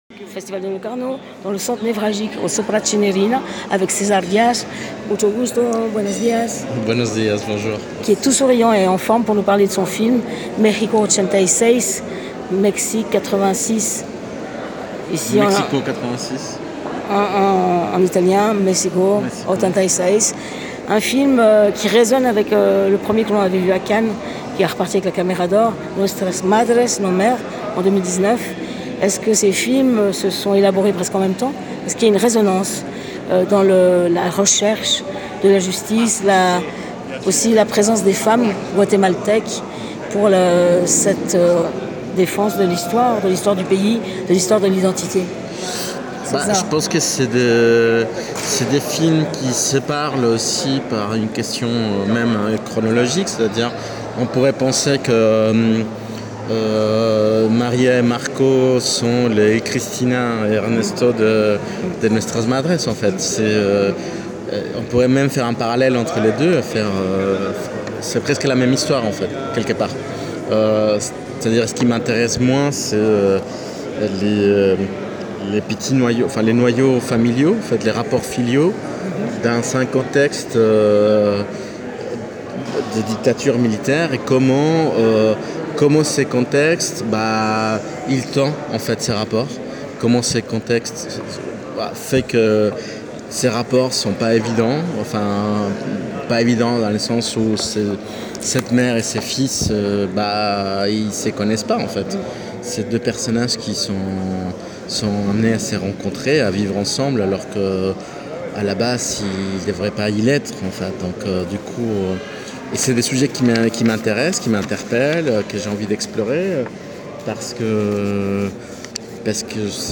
Rencontre - j:mag